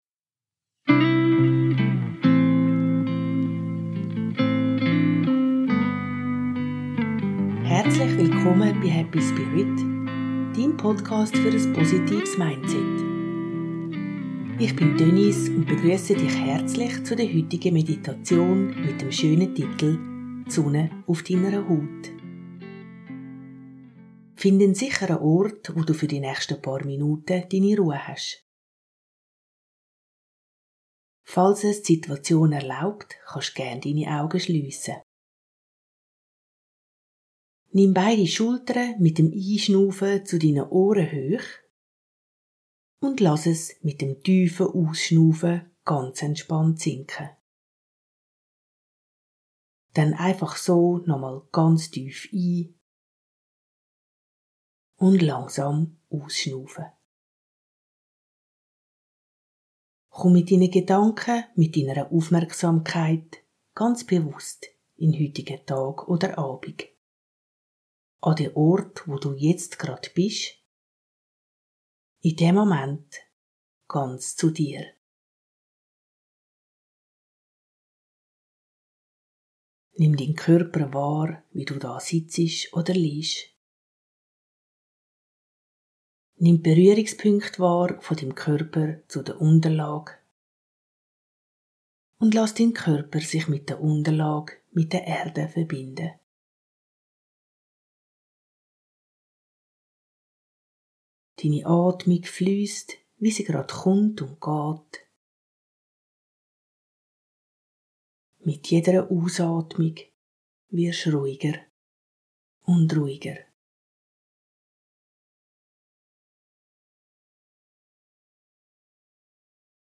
#62 Meditation - Sonne auf deiner Haut